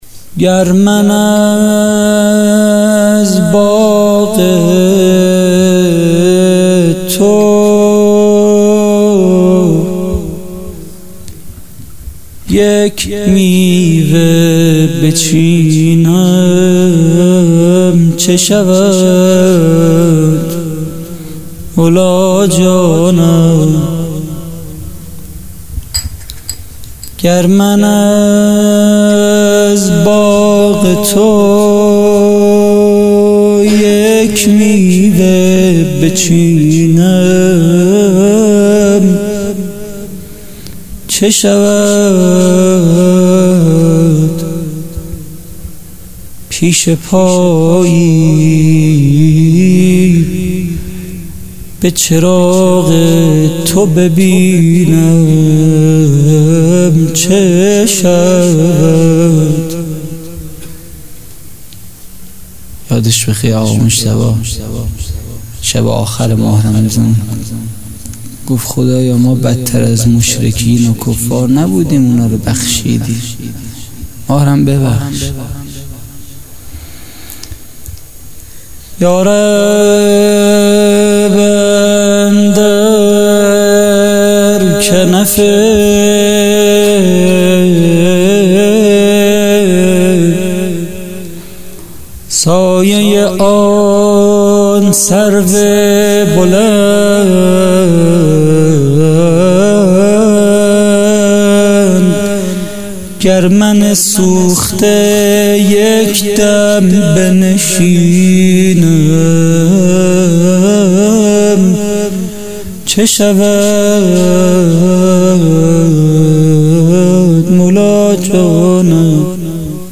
مراسم مناجات و وداع با ماه مبارک رمضان